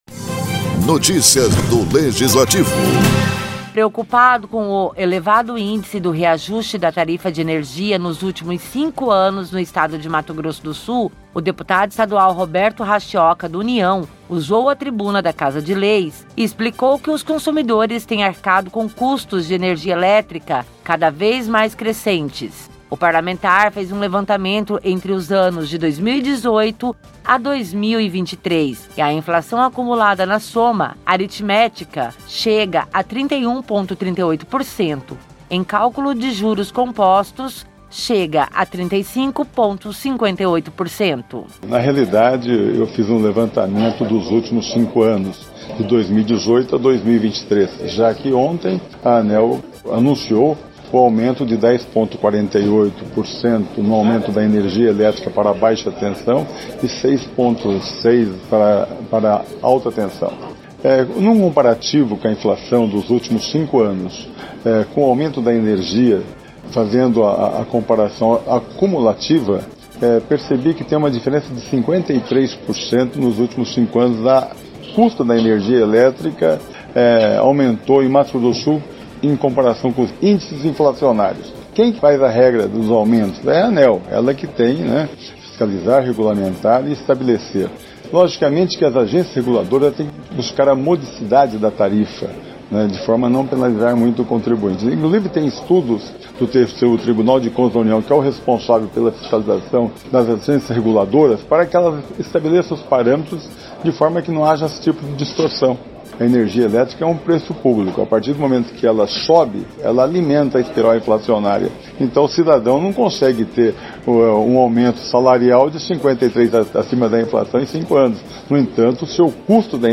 O deputado estadual Roberto Hashioka, do União, usou a tribuna da Casa de Leis para enfatizar o alto índice de aumento que incide na energia elétrica do Estado de Mato Grosso do Sul.